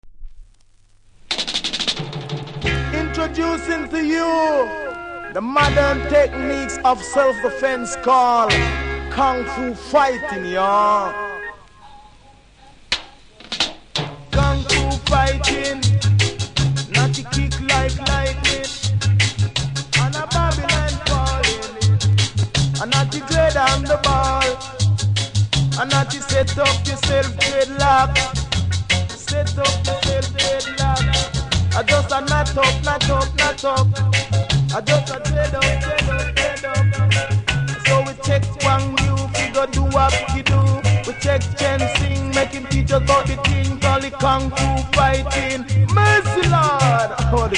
REGGAE 70'S
多少うすキズありますが音は良好なので試聴で確認下さい。